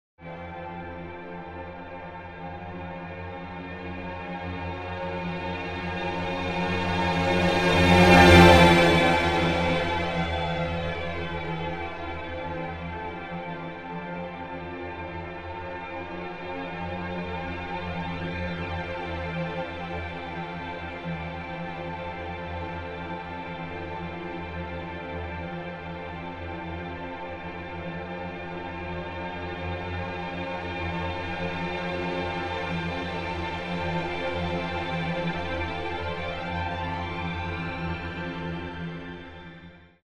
A freeze from Mozart's minuet, which rolls by us at 120 km/h:
The same freeze, now on the trace predetermined by the lemniscates: